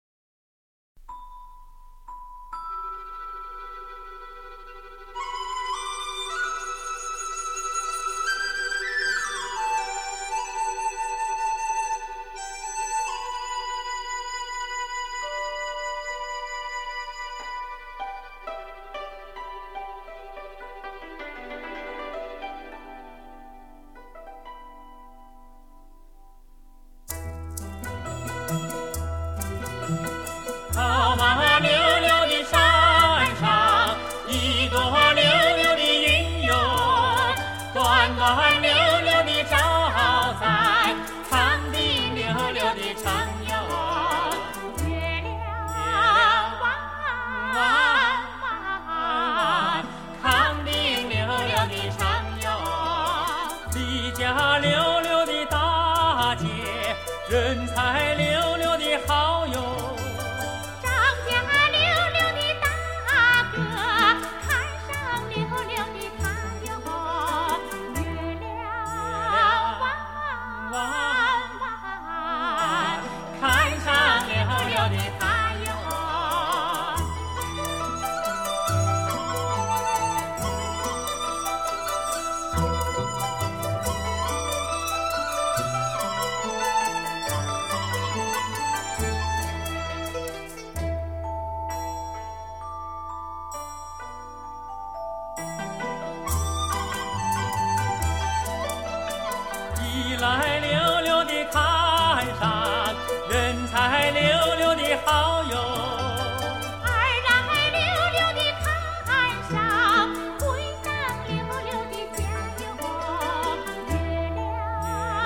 百年唱片民歌经典